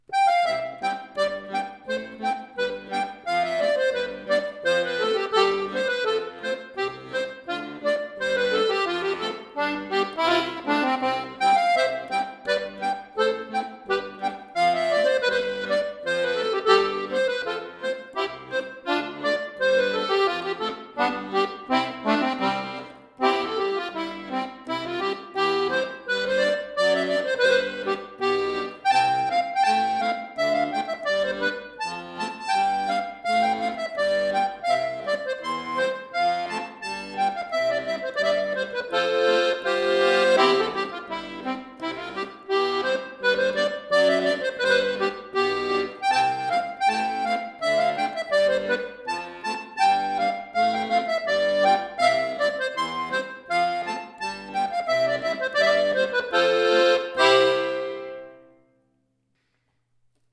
Folksong , Irish